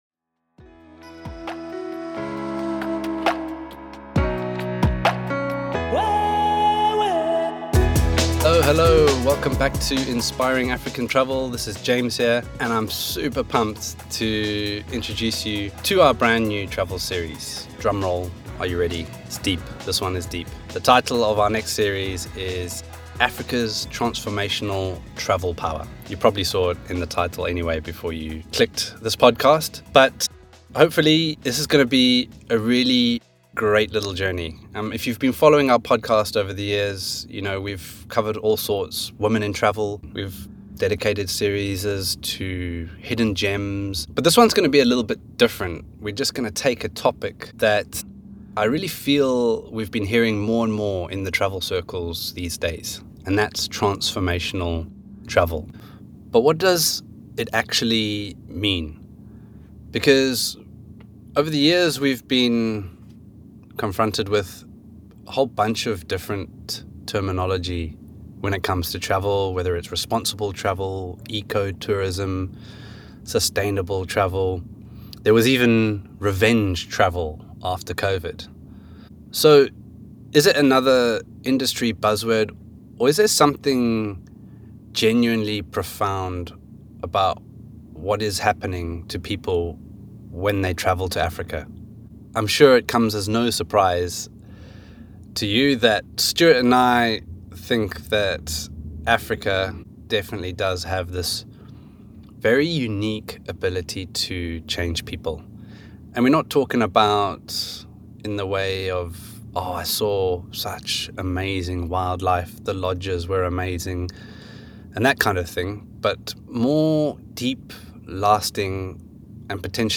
During their journey through the South African countryside, they each share their three most profound transformational travel experiences in Africa, setting the foundation for this series exploring how Africa changes travellers in ways that go far beyond typical holidays.